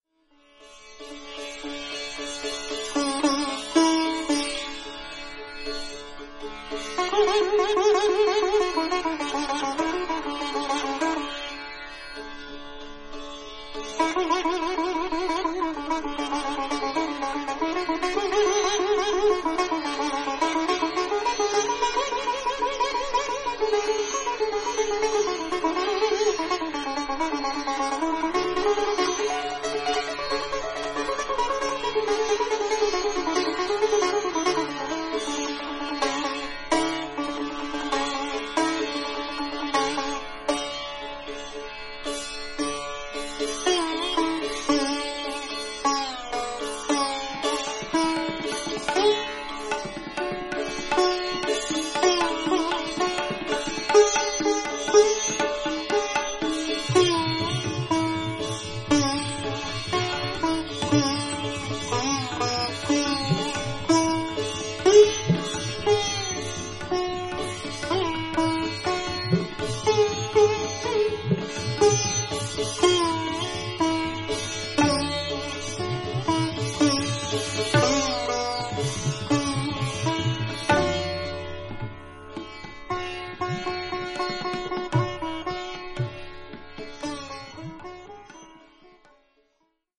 男性奏者が多かったこの分野で、最も優れた女性奏者の一人とみなされている、卓越された演奏が堪能できます。
タブラ
タンブラ